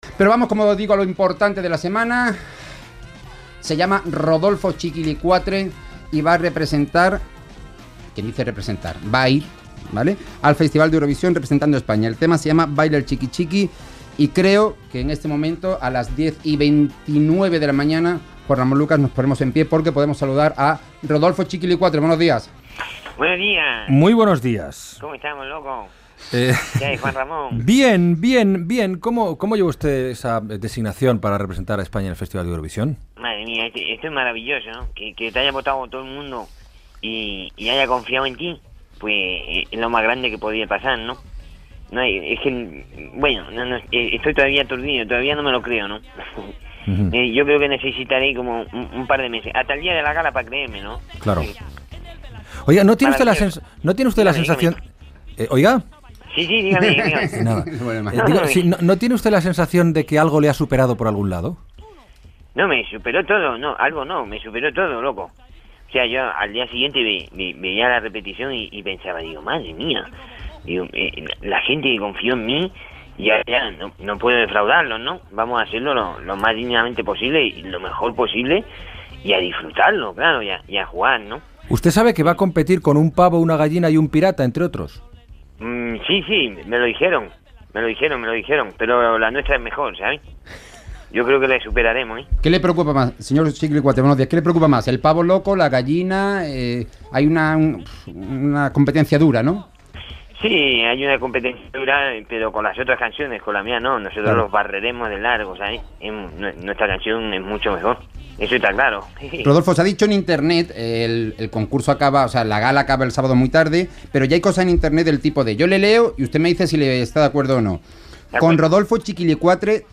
Entrevista a Rodolfo Chikilicuatre (Vavid Fernández) que va ser escollit pels espectadors per representarà a Televisió Espanyola al Festival d'Eurovisió
Info-entreteniment